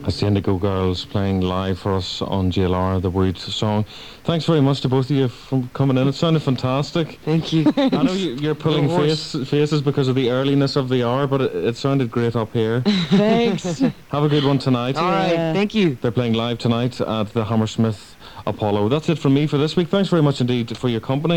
lifeblood: bootlegs: 1994-06-03: greater london radio - england
07. interview (0:21)